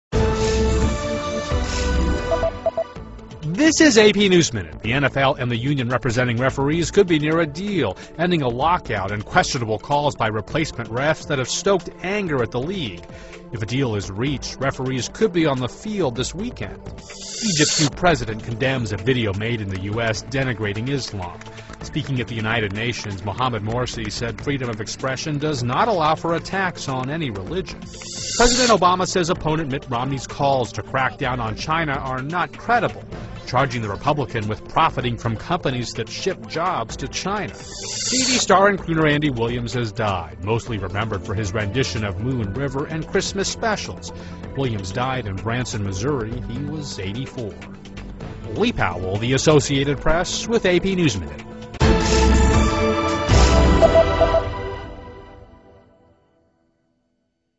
在线英语听力室美联社新闻一分钟 AP 2012-10-04的听力文件下载,美联社新闻一分钟2012,英语听力,英语新闻,英语MP3 由美联社编辑的一分钟国际电视新闻，报道每天发生的重大国际事件。